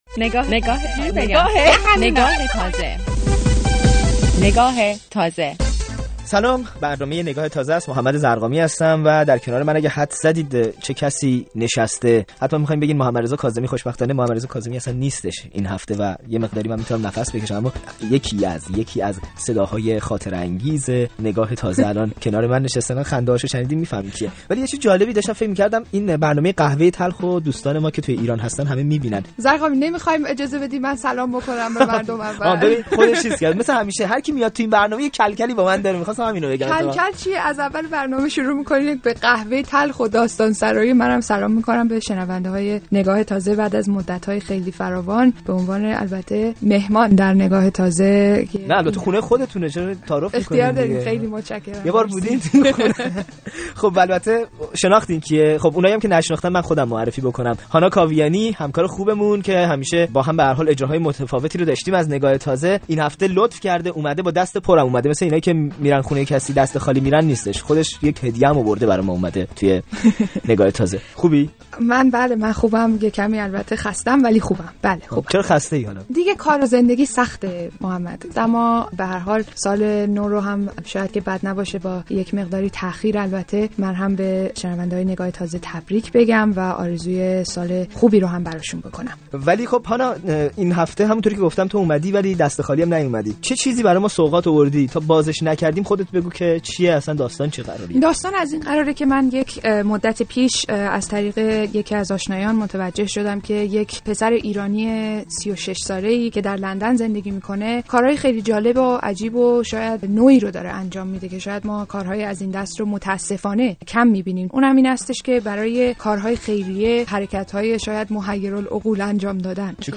برنامه رادیویی «نگاه تازه» و گفت و گو